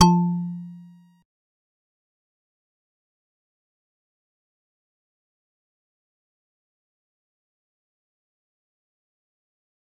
G_Musicbox-F3-pp.wav